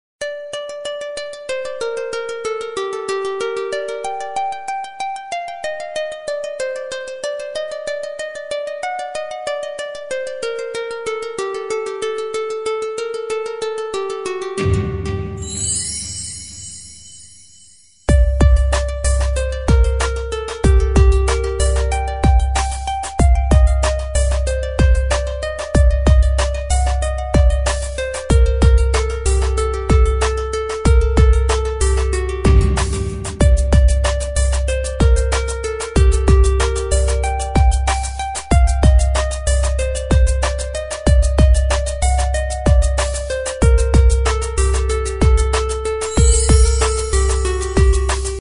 • Качество: 128, Stereo
без слов
инструментальные